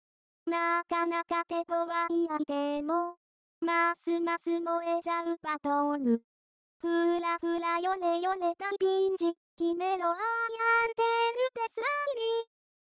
ボカロのリハビリに、こんなものを作ってみました。
ちなみに、実はリンもレンも声色を変える事ができるのです（ジェンダーファクター機能）。２番目と３番目は男っぽく補正してある。